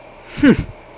Hmmph! Sound Effect
hmmph-1.mp3